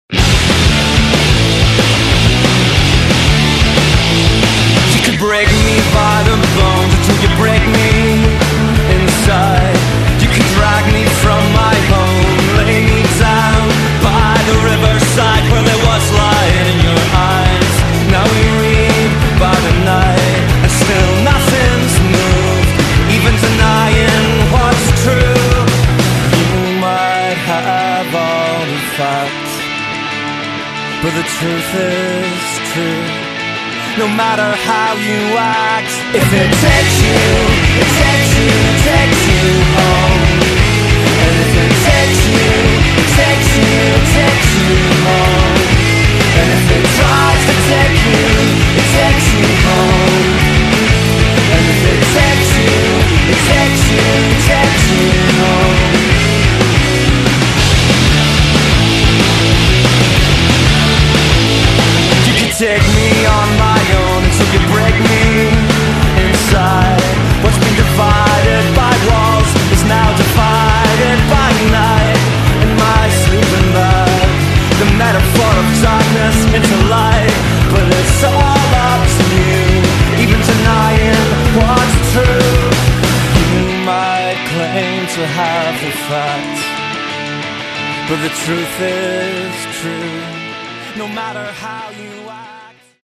Canzoni semplici ma grande intensità vocale.